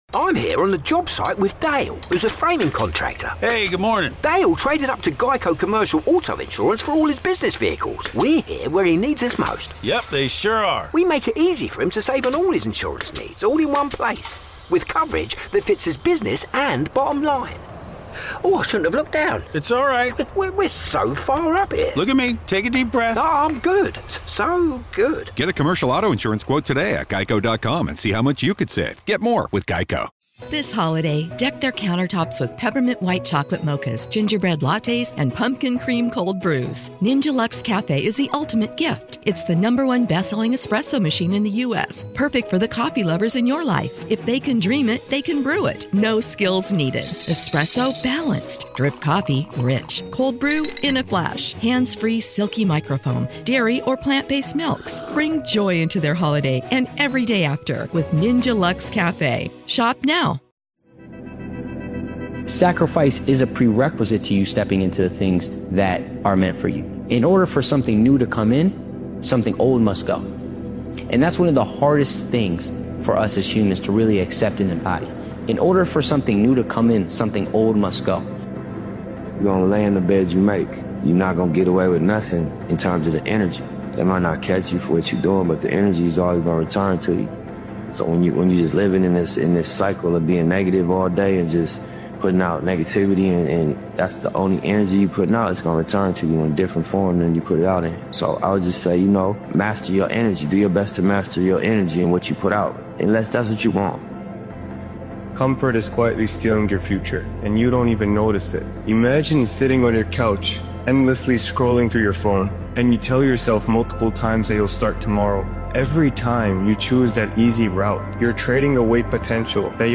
This powerful motivational speeches compilation reminds you that every achievement begins with imagination and clarity.